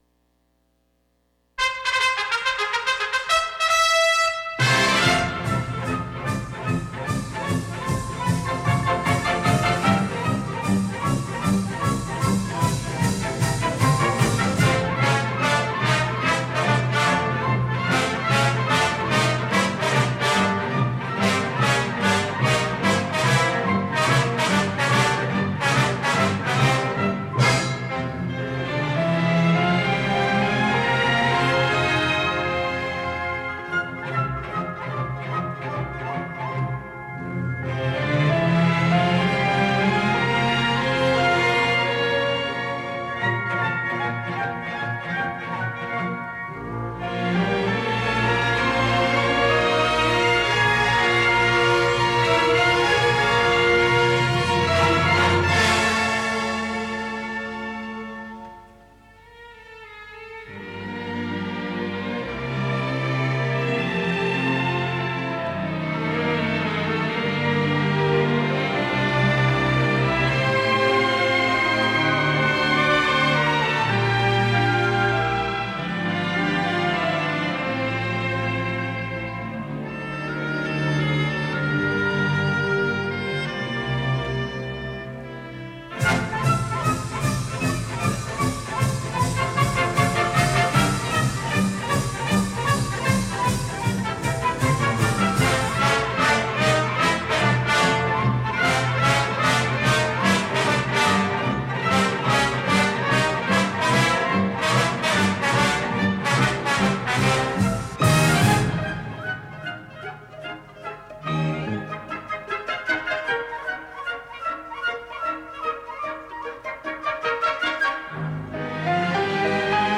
movie music